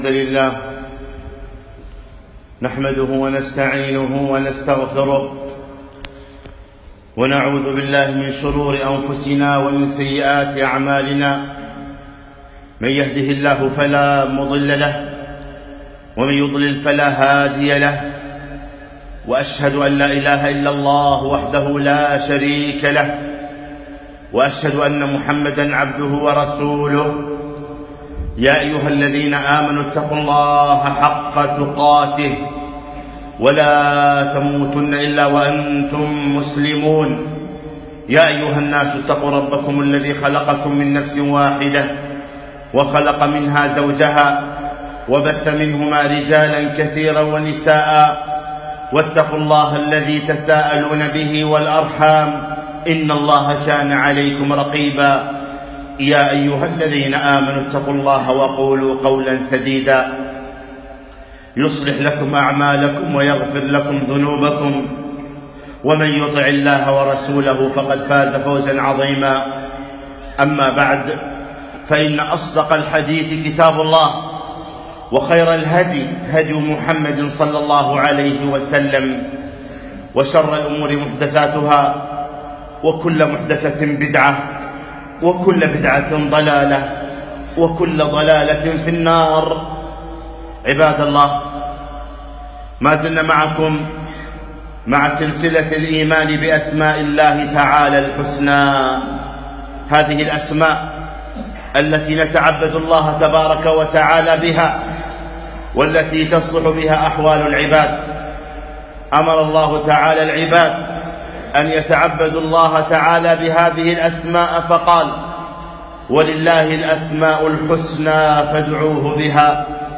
خطبة - إرشاد الأنام إلى اسم الله االسلام